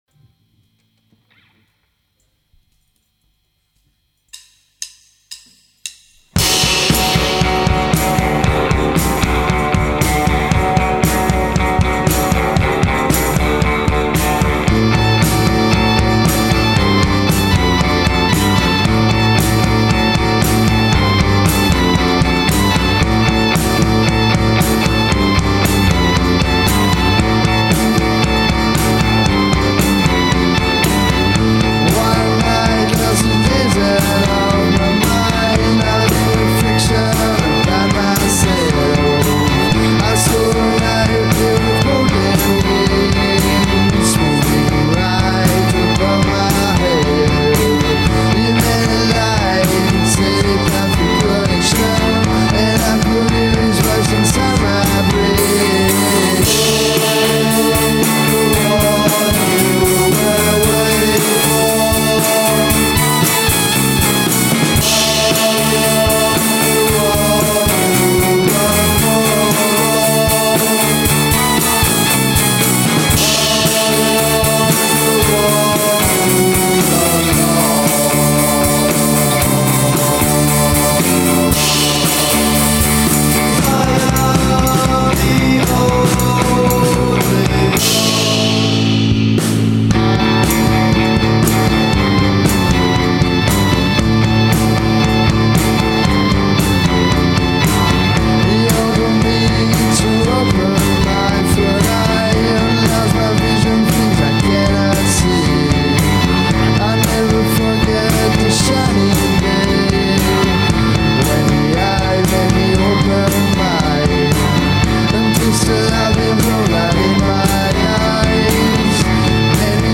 electric gospel